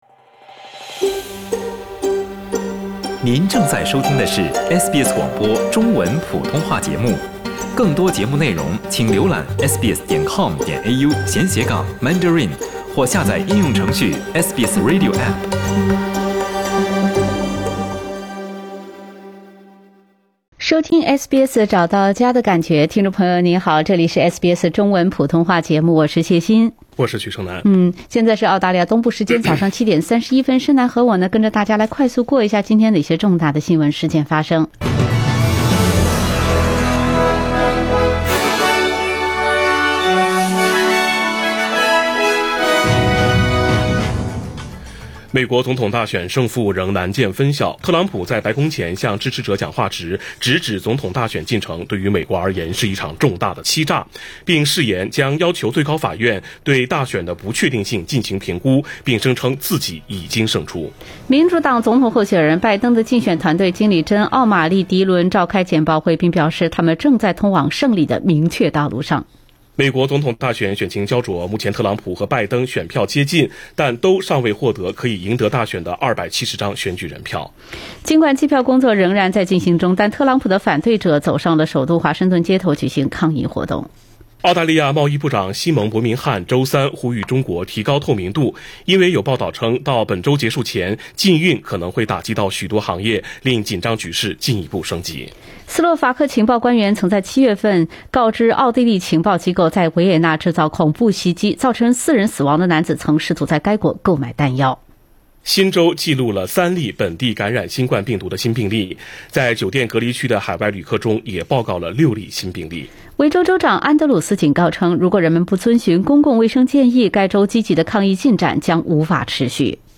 SBS早新闻（11月05日）